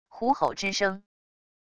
虎吼之声wav音频